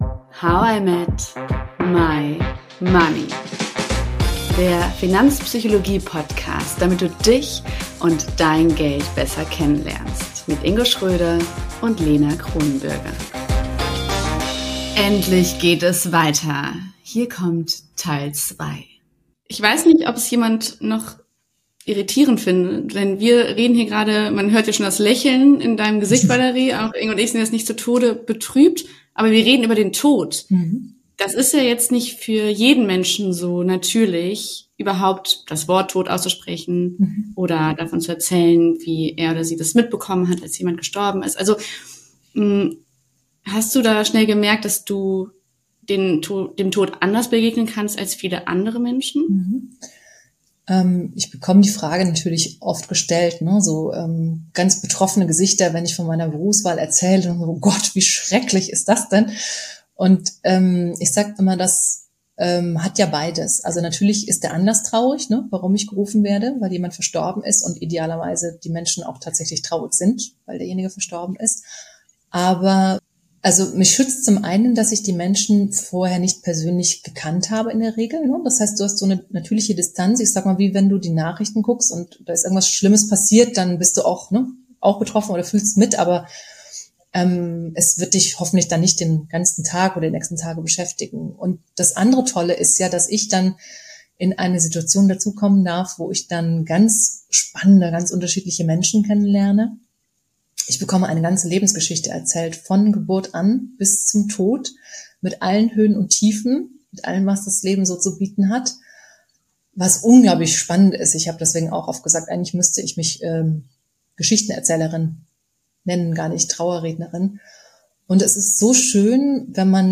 Ein tiefes Gespräch über Abschied, Ehrlichkeit und die kleinen Entscheidungen, die wir zu Lebzeiten treffen sollten.